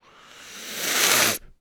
snake_hiss_03.wav